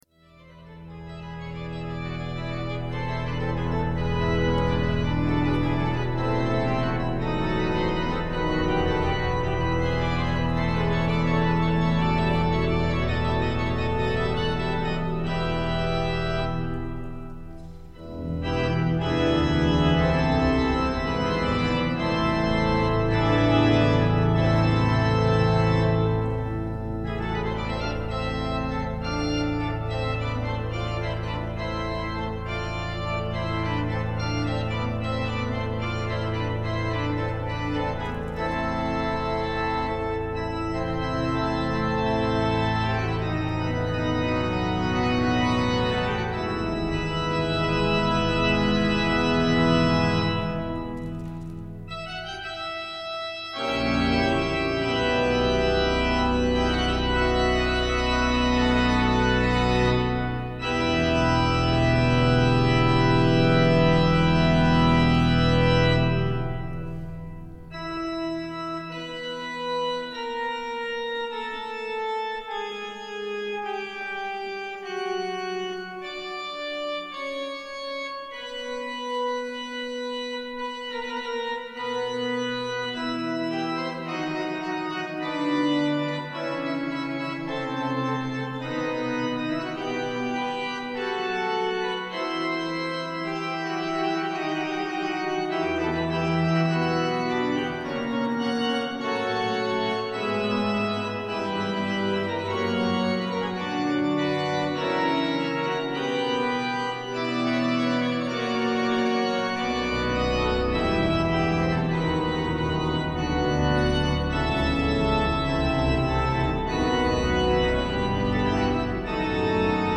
Concierto de Órgano a Luz de Vela
al nuevo gran Órgano Allen de la Catedral Metropolitana de Valladolid.